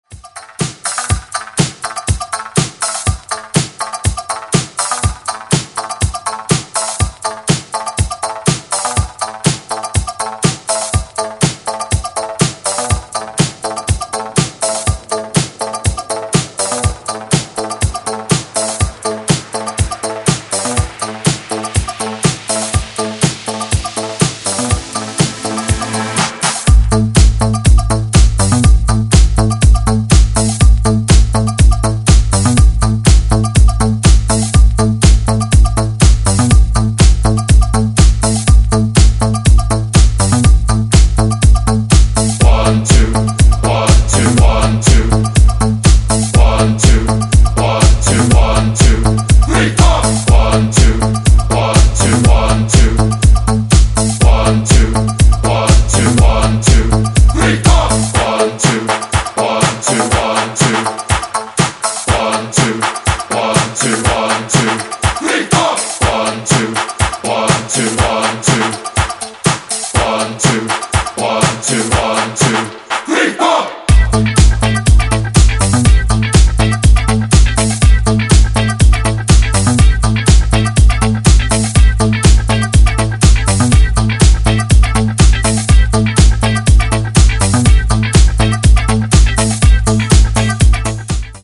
アップ・ハウスでフロア重視の全4バージョン
ジャンル(スタイル) HOUSE / DISCO HOUSE